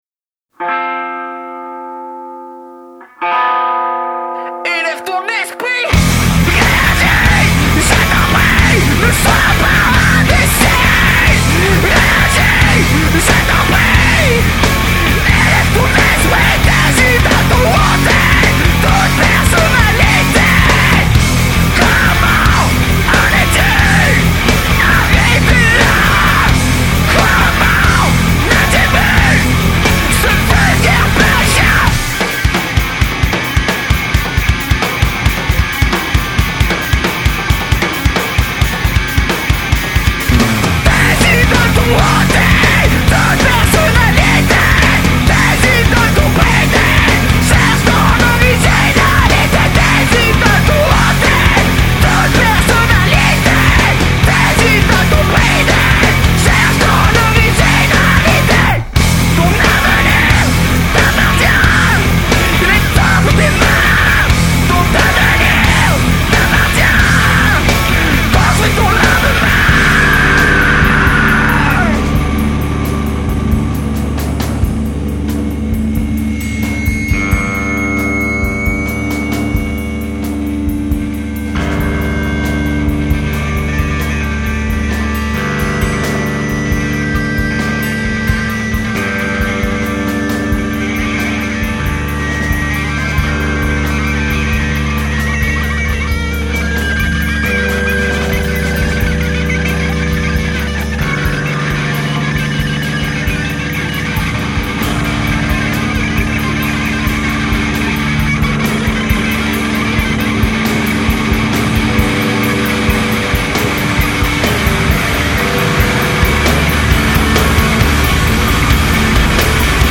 [Neo Metal/Emocore]
Vocals
Guitars
Bass
Drums